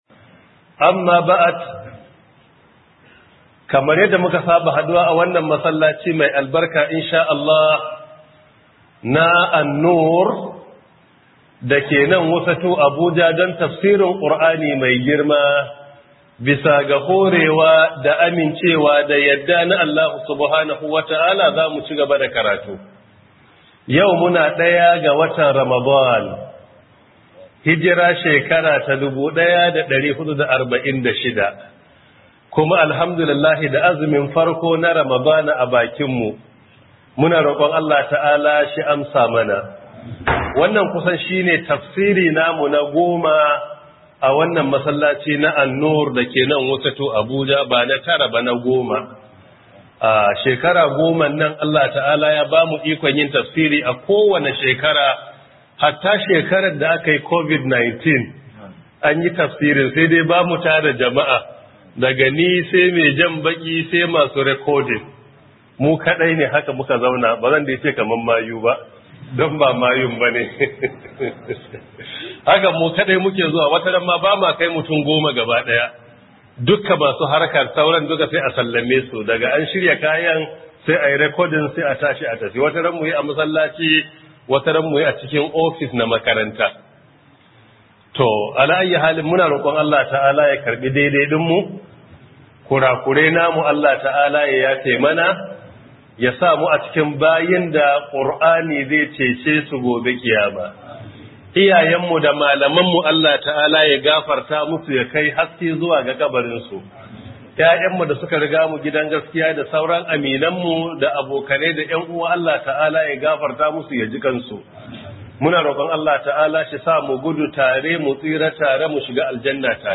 Ramadan Tafseer 001
Ramadan Tafseer by Prof Isah Ali Ibrahim Pantami 1446AH/2025